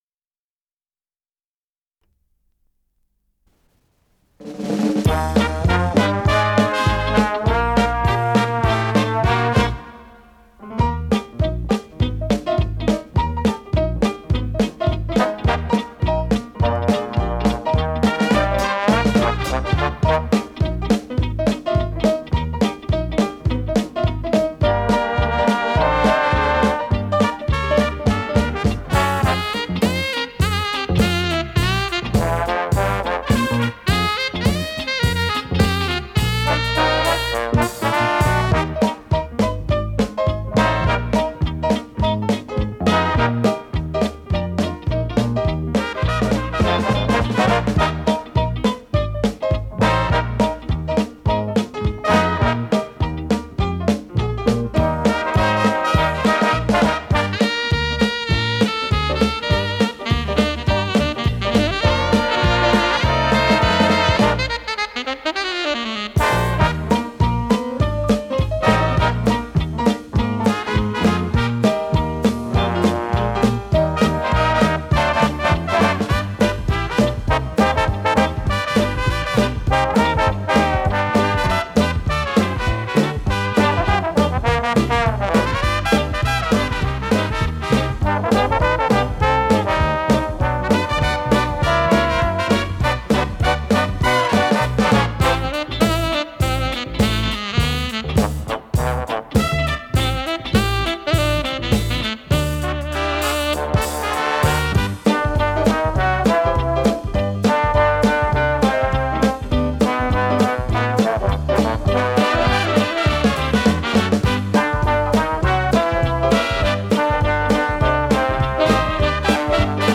инструментальная пьеса
ВариантДубль моно